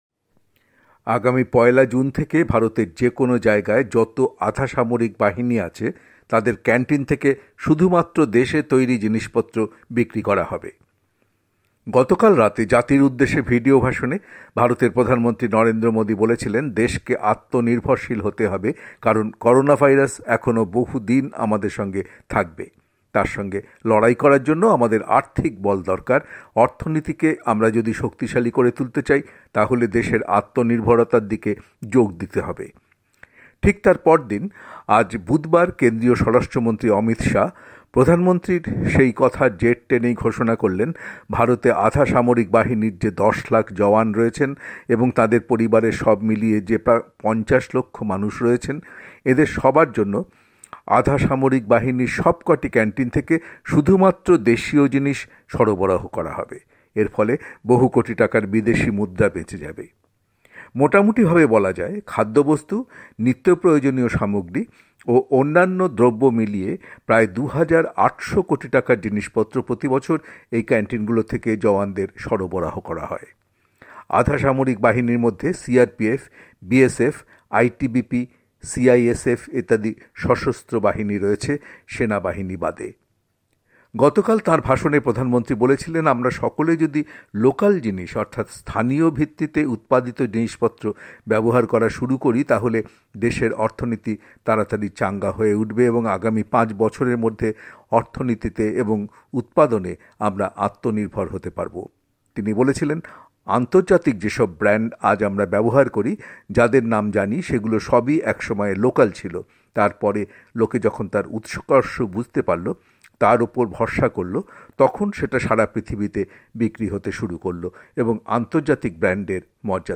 কলকাতা থেকে
রিপোর্ট।